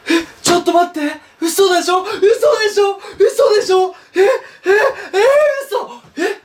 etsu chiyotsutodai tsute xu deshiyo xu deshiyo xu deshiyo Meme Sound Effect